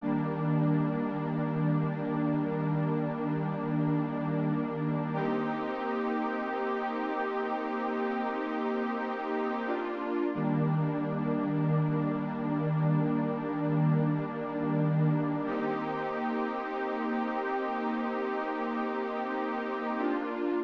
08 pad A.wav